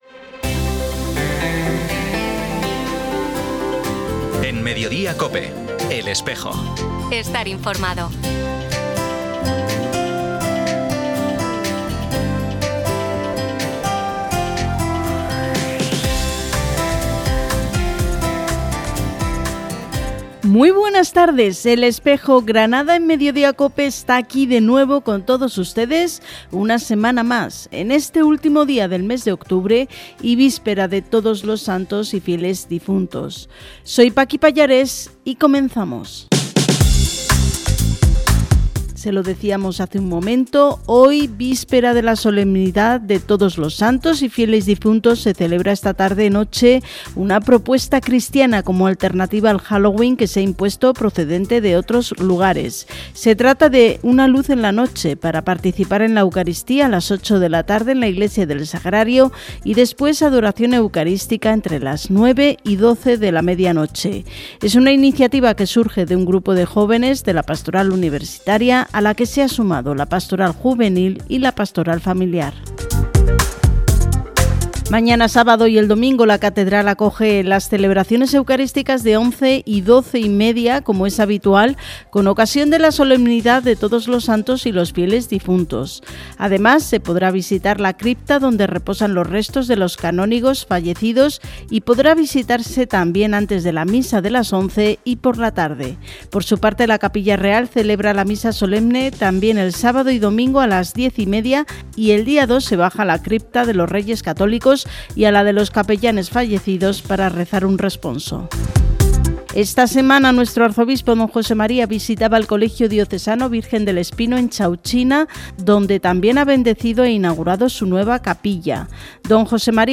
13/09/2025: Homilía de Mons. Satué en su toma de posesión
Ofrecemos la retransmisión de la toma de posesión de Mons. Satué como Obispo de Málaga ofrecida por COPE Málaga y TRECE desde la Catedral de la Encarnación, el 13 de septiembre de 2025.